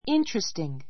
interesting 小 A1 íntəristiŋ イ ンタレ ス ティン ぐ 形容詞 比較級 more interesting 最上級 most interesting おもしろい , 興味深い ✓ POINT あるものが人の興味を呼び起こす内容を持っていることをいう.